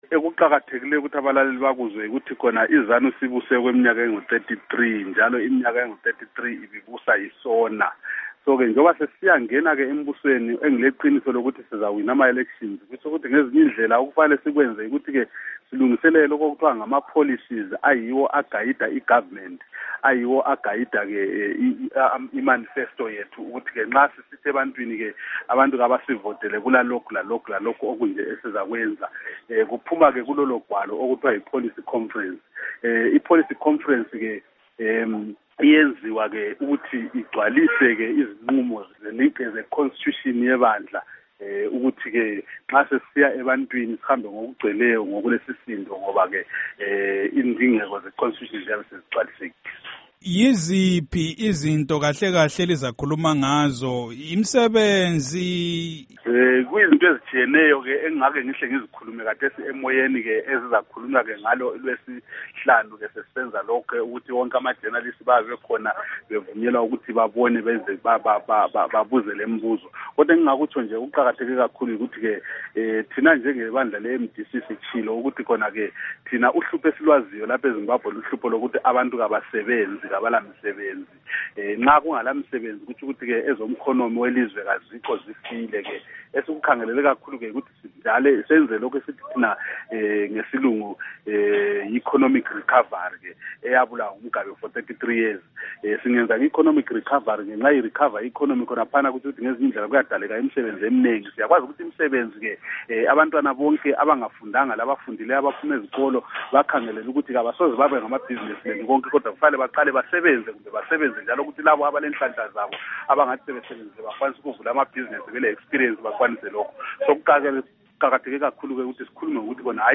Ingxoxo LoMnu Abednico Bhebhe